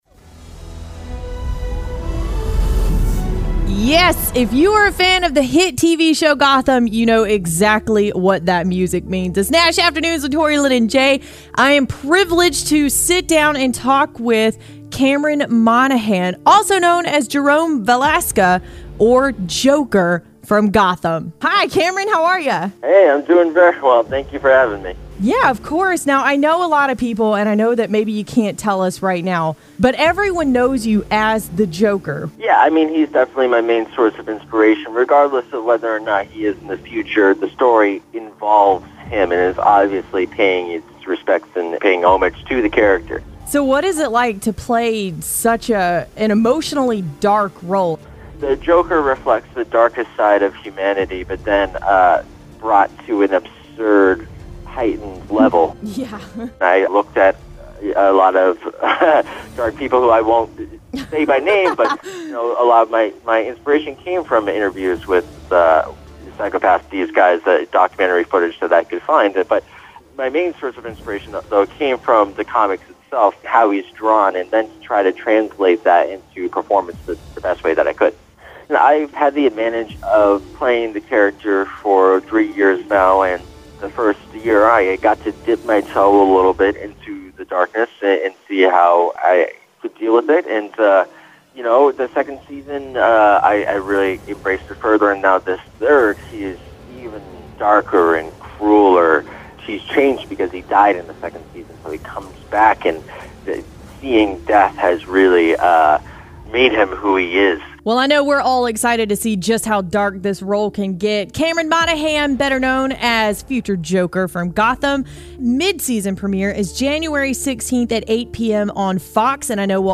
INTERVIEW: Jerome "Joker" of Gotham